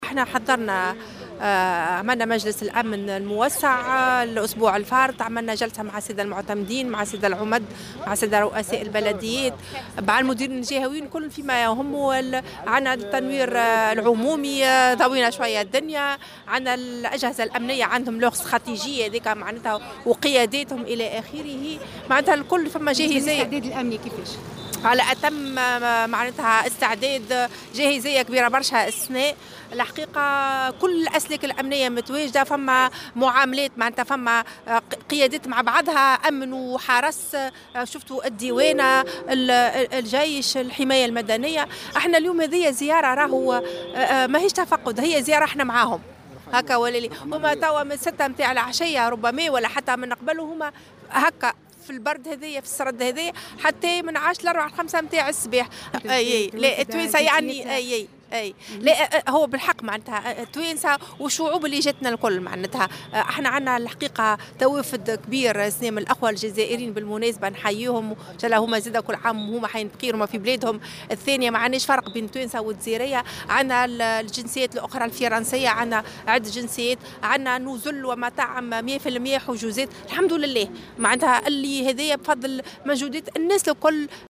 وأكدت في تصريح اليوم لمراسلة "الجوهرة أف أم" أن الجهة شهدت توافد أعداد كبيرة من السياح الجزائريين وغيرهم، مشيرة إلى أن نسبة امتلاء بعض النزل بالجهة بلغت نسبة 100 بالمائة.